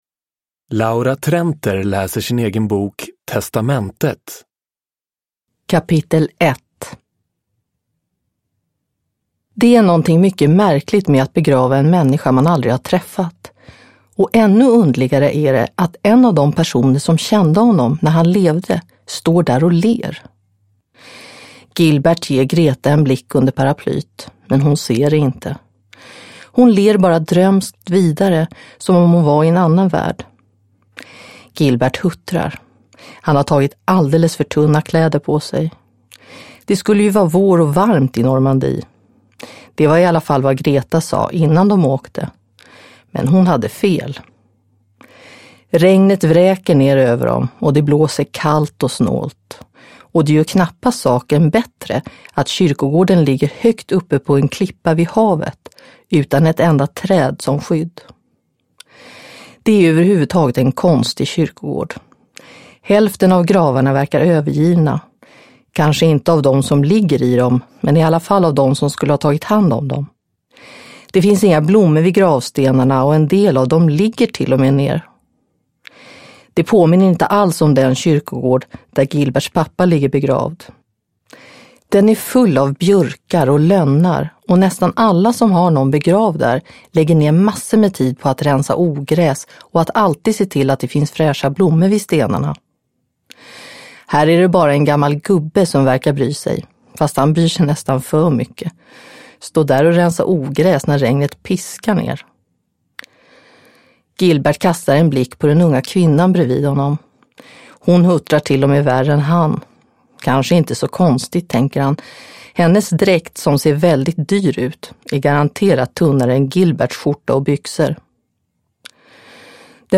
Testamentet – Ljudbok – Laddas ner
Uppläsare: Laura Trenter